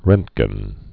(rĕntgən, -jən, rŭnt-) or Rönt·gen (rœntgən), Wilhelm Conrad 1845-1923.